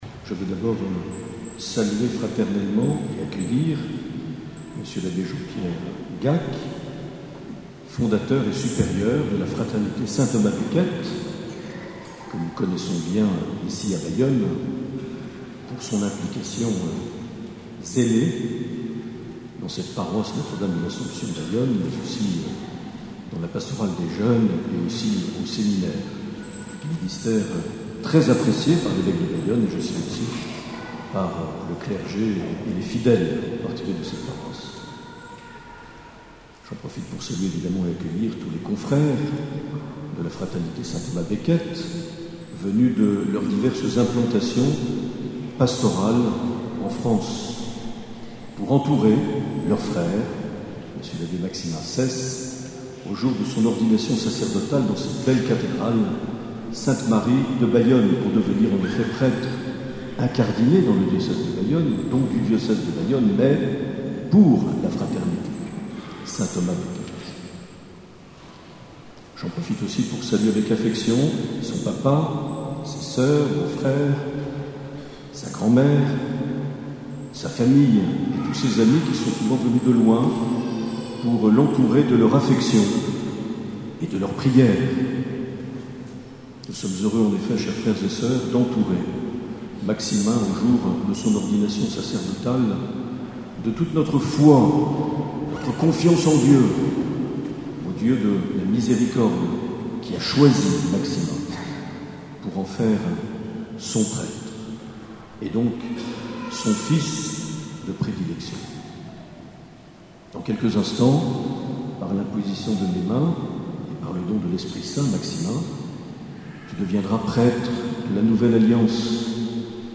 Accueil \ Emissions \ Vie de l’Eglise \ Evêque \ Les Homélies \ 1er mai 2018
Une émission présentée par Monseigneur Marc Aillet